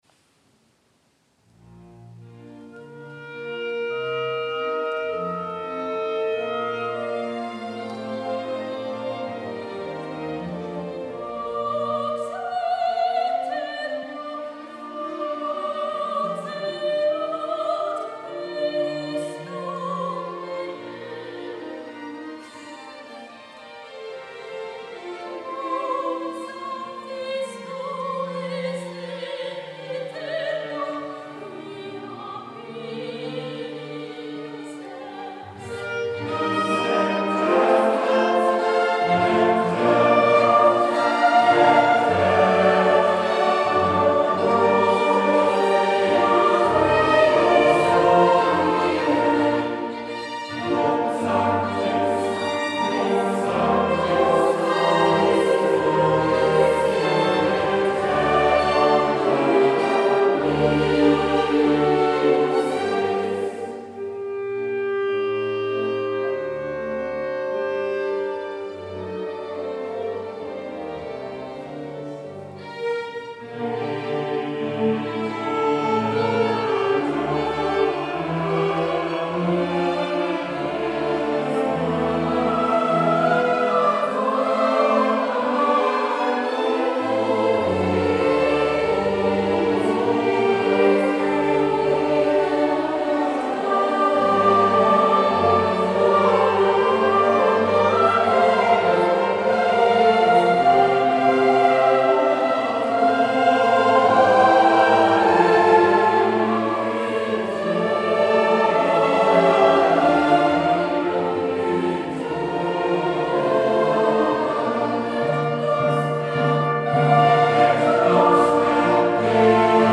Rückblick Chorkonzert „Mozart Requiem“
Es war ein wundervolles Klangerlebnis und eine ergriffene Stimmung in der St. Meinrads Kirche.